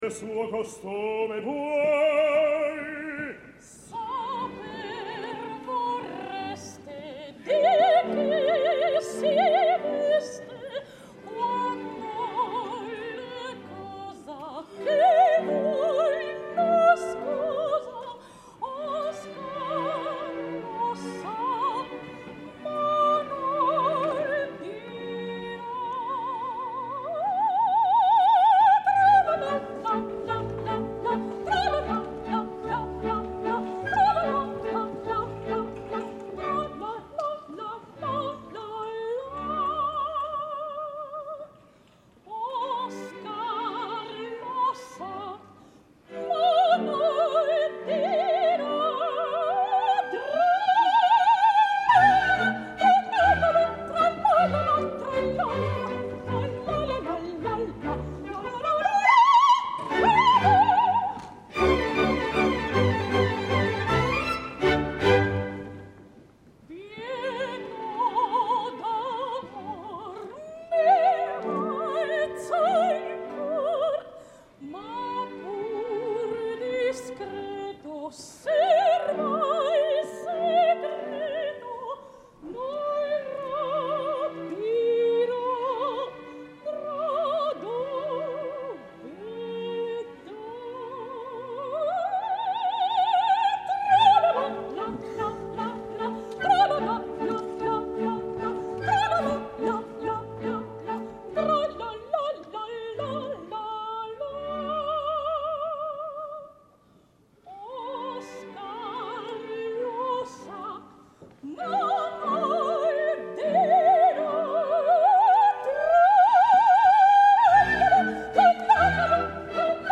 soprano valenciana